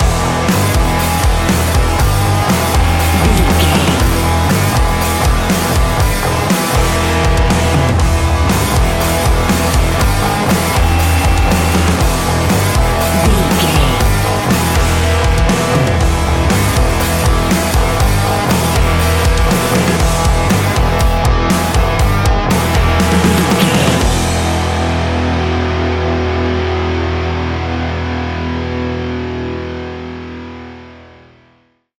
Ionian/Major
E♭
heavy rock
heavy metal
instrumentals